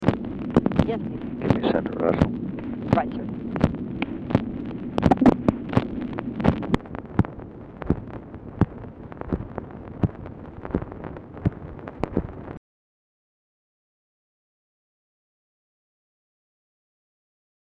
Format Dictation belt
Location Of Speaker 1 Mansion, White House, Washington, DC
Speaker 2 TELEPHONE OPERATOR Specific Item Type Telephone conversation